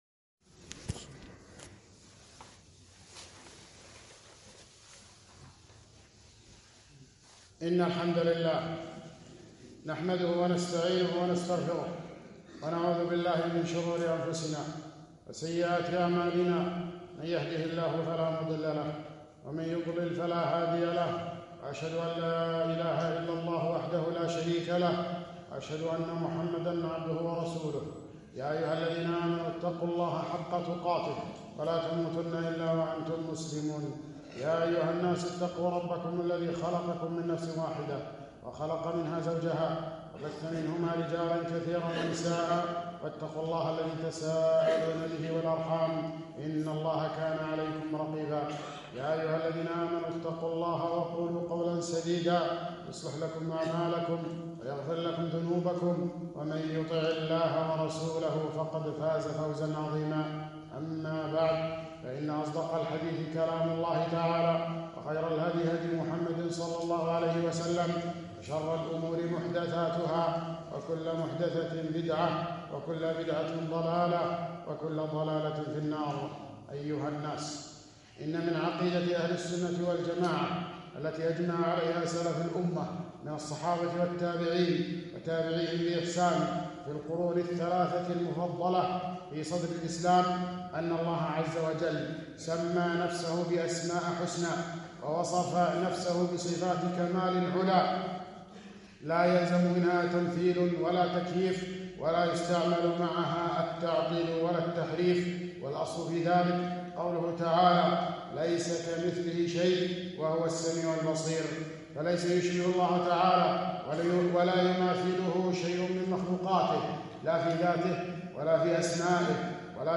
خطبة - علو الله على خلقه